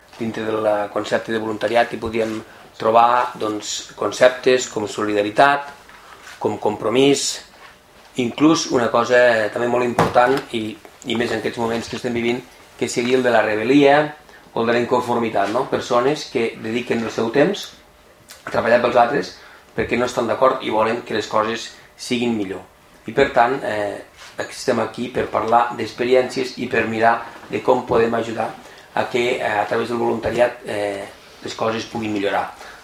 Una seixantena de persones han assistit a la conferència que ha estat presentada pel vicepresident quart de la Diputació de Lleida, Jordi Latorre